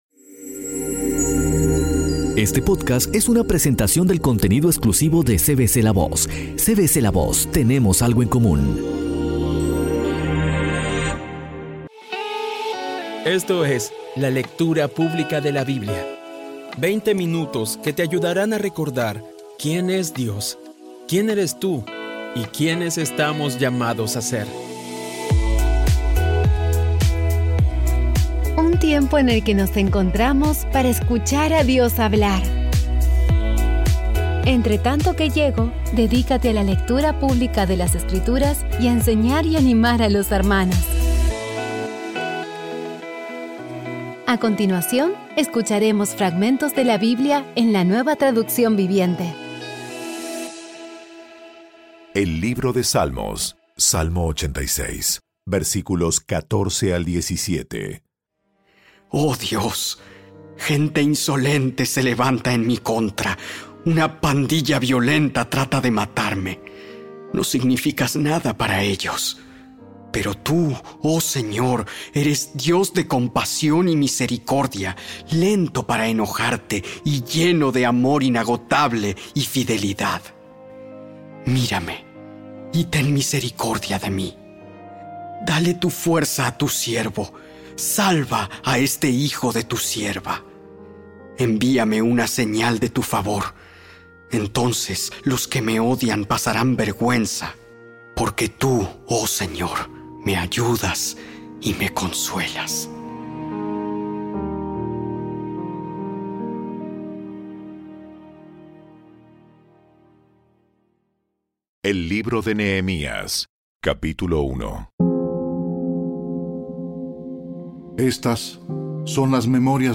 Audio Biblia Dramatizada Episodio 209
Poco a poco y con las maravillosas voces actuadas de los protagonistas vas degustando las palabras de esa guía que Dios nos dio.